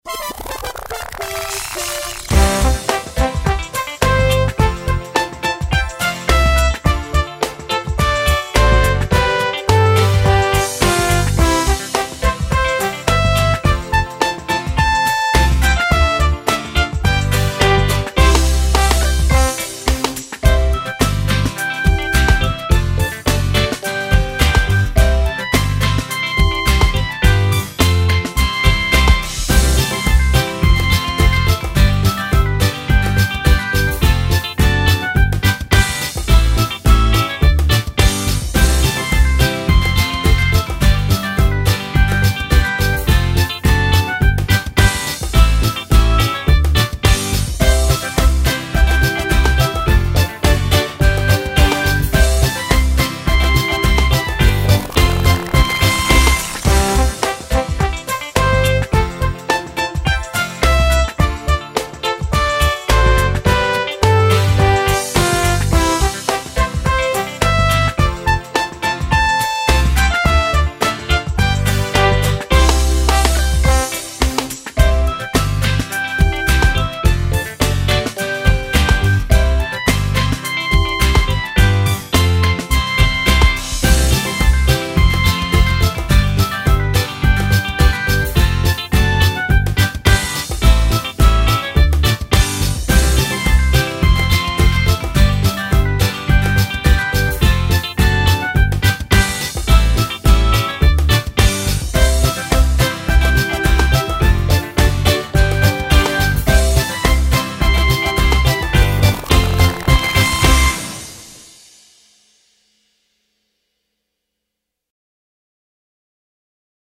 繞口令(純伴奏版) | 新北市客家文化典藏資料庫